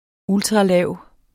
Udtale [ -ˈlæˀw ]